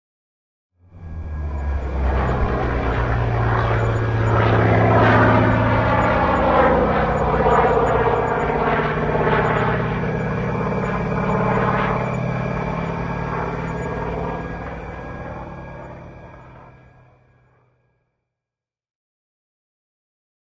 Propeller Aircraft » A propeller aircraft passing (L toR) realistic
描述：propeller aircraft passing left to right. Needs dehiss.
标签： aircraft propeller request
声道立体声